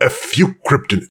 woodboxdestroyed04.ogg